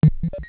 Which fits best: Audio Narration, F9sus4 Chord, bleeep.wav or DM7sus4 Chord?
bleeep.wav